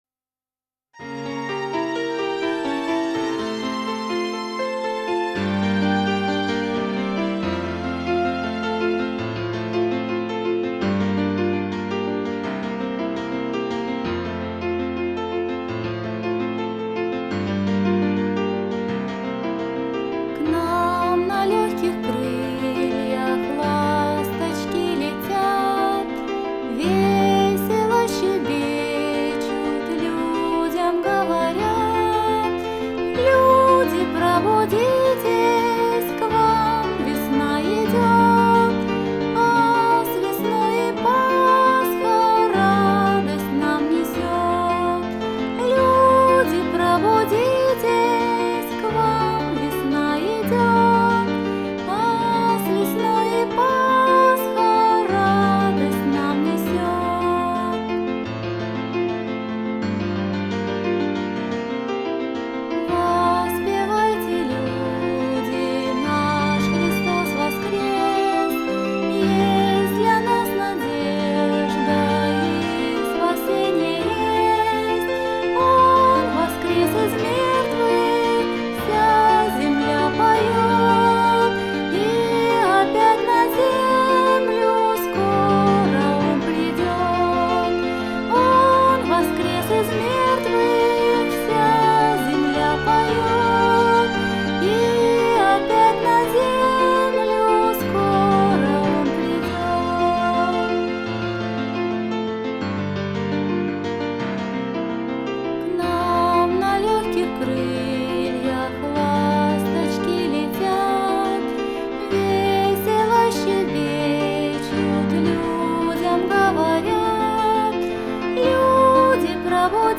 Easter songs performed by children are characterized by a special emotionality.